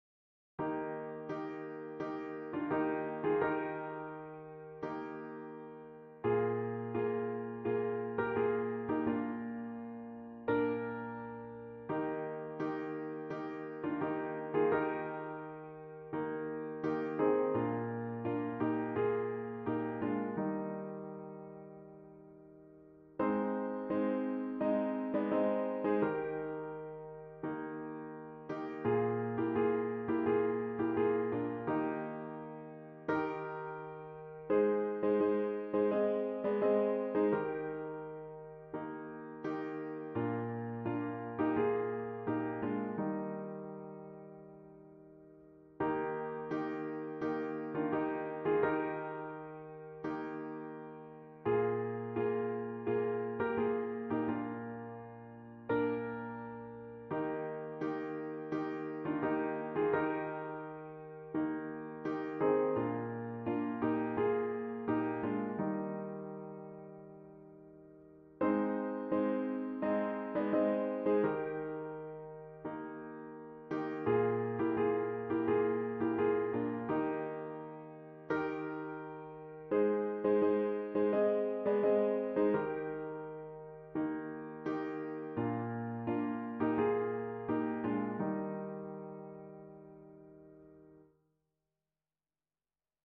A traditional Baptist hymn
for piano